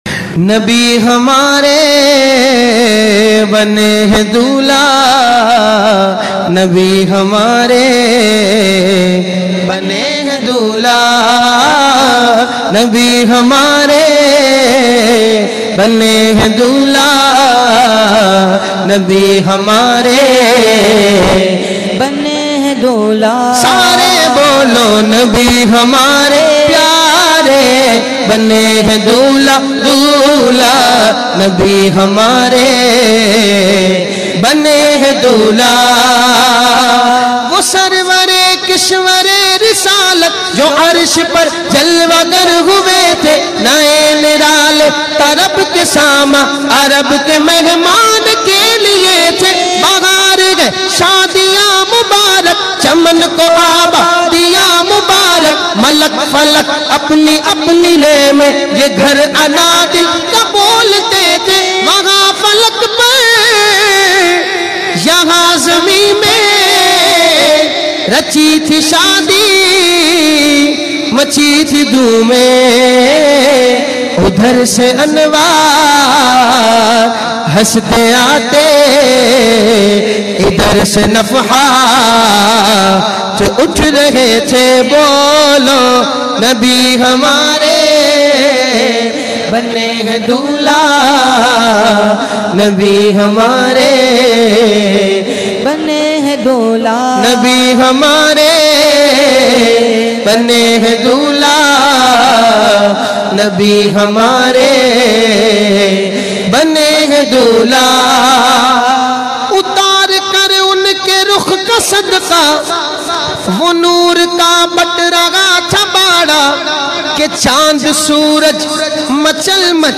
Naat in a Heart-Touching Voice
devotional music